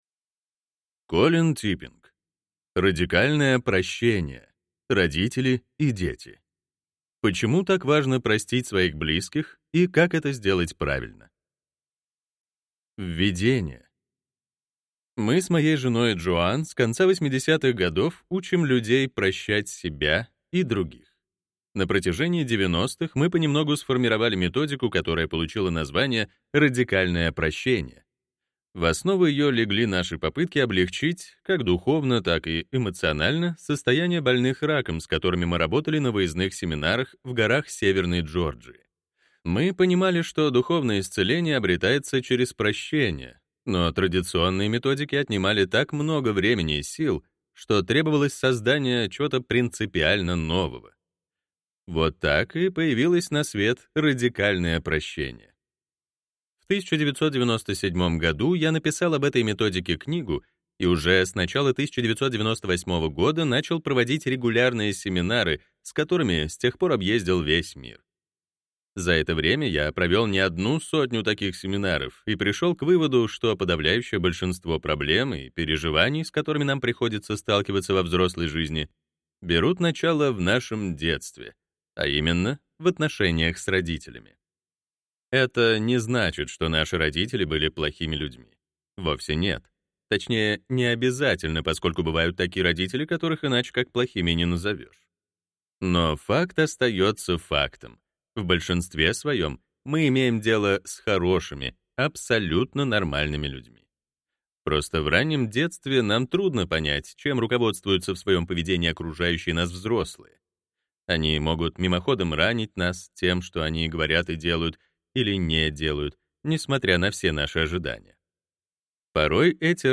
Аудиокнига Радикальное Прощение: родители и дети. Почему так важно простить своих близких и как сделать это правильно | Библиотека аудиокниг